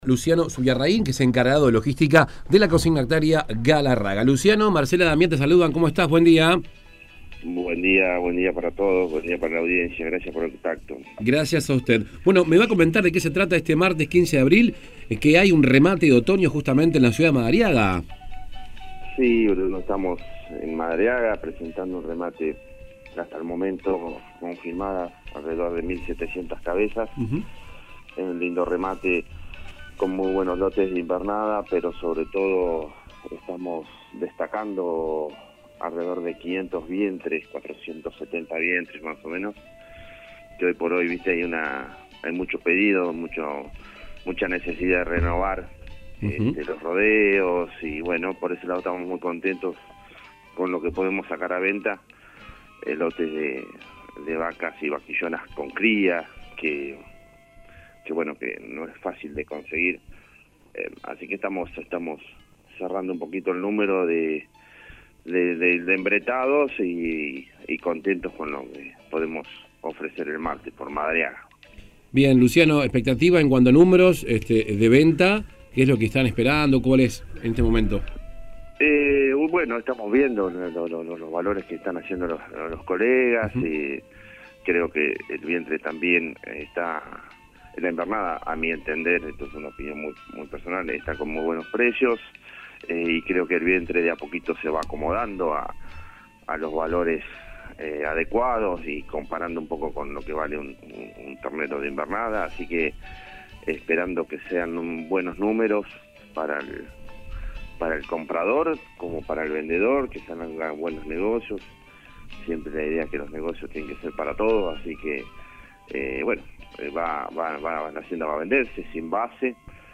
Vía telefónica